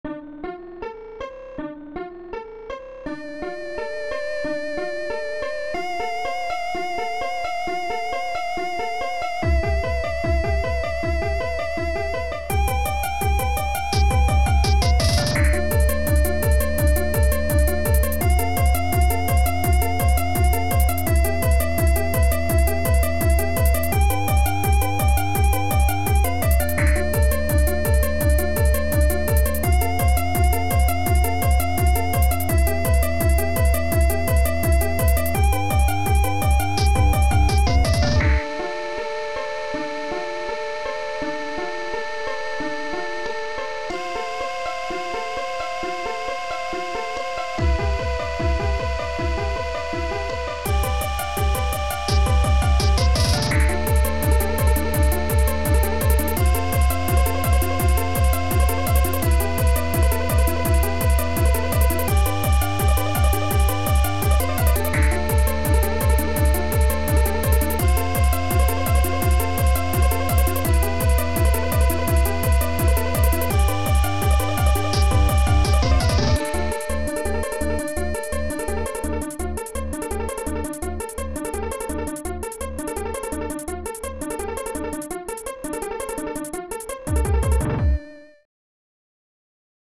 Impulse Tracker Module